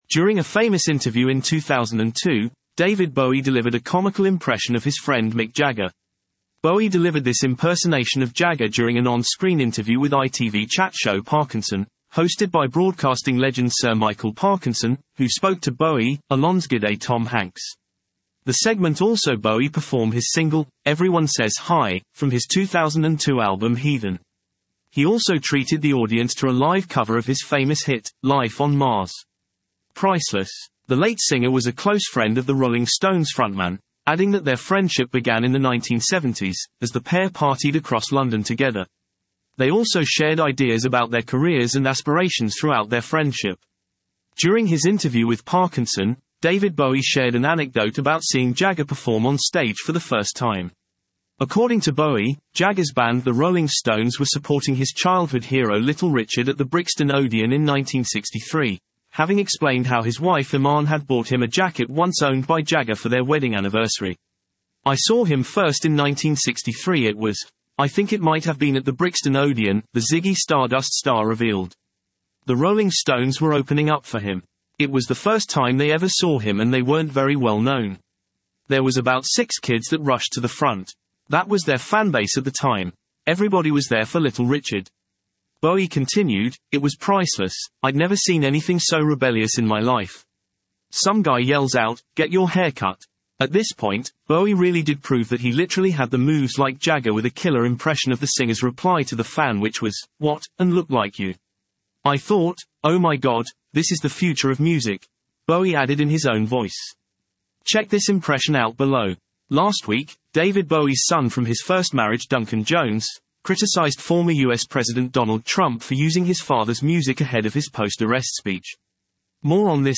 During a famous interview in 2002, David Bowie delivered a comical impression of his friend Mick Jagger.
Bowie delivered this impersonation of Jagger during an on screen interview with ITV chat show Parkinson, hosted by broadcasting legend Sir Michael Parkinson, who spoke to Bowie, alonsgide Tom Hanks.